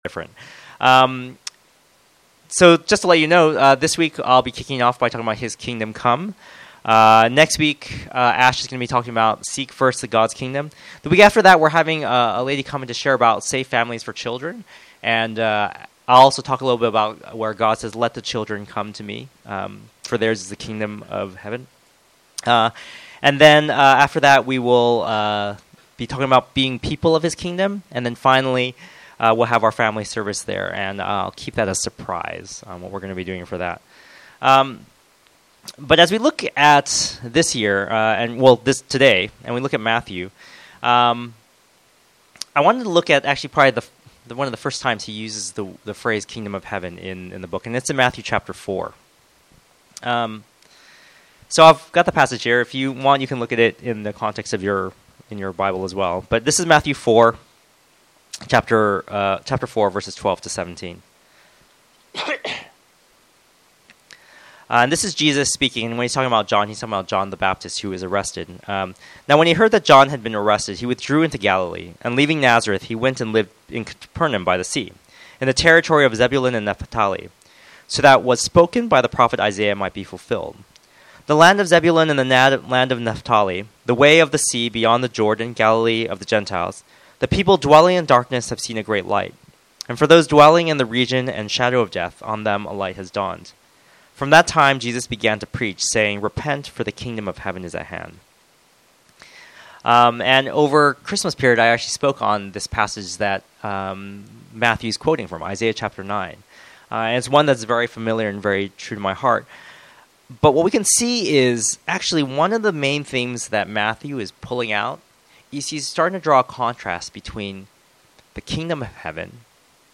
Download Filename 160103Sermon.mp3 Filesize 17.13 MB Version 1 Date added 3 January 2016 Downloaded 424 times Category Sermon Audio Tags 2016 , His Kingdom Come